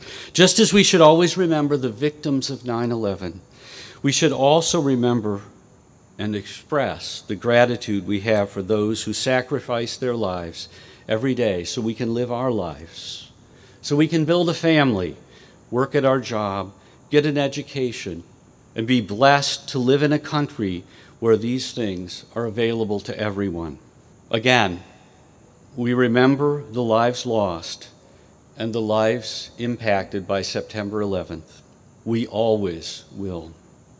This morning, members of the IUP community gathered in the Oak Grove to pay tribute to the events of the September 11 attacks in New York, Washington D.C. and Shanksville.